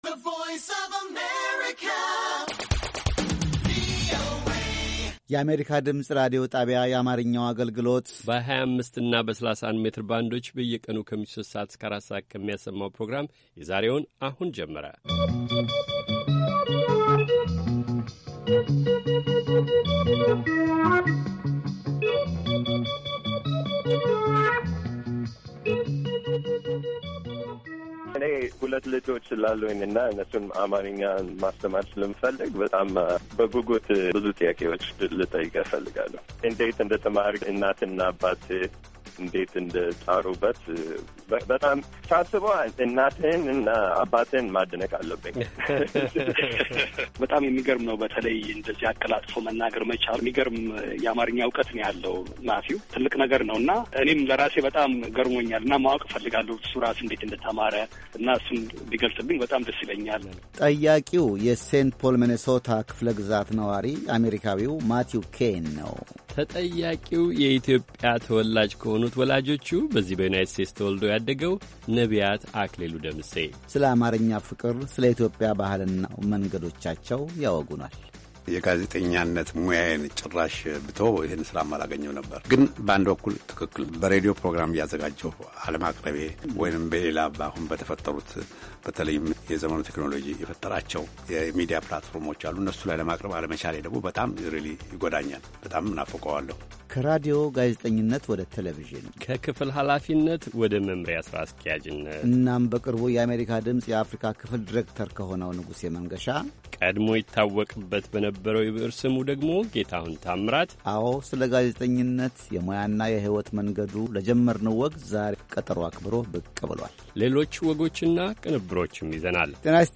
ከምሽቱ ሦስት ሰዓት የአማርኛ ዜና
ቪኦኤ በየዕለቱ ከምሽቱ 3 ሰዓት ጀምሮ በአማርኛ፣ በአጭር ሞገድ 22፣ 25 እና 31 ሜትር ባንድ ከሚያሠራጨው የ60 ደቂቃ ዜና፣ አበይት ዜናዎች ትንታኔና ሌሎችም ወቅታዊ መረጃዎችን የያዙ ፕሮግራሞች በተጨማሪ ከሰኞ እስከ ዐርብ ከምሽቱ 1 ሰዓት እስከ 1 ሰዓት ተኩል በአማርኛ የሚተላለፍ የግማሽ ሰዓት ሥርጭት በ1431 መካከለኛ ሞገድ ላይ አለው፡፡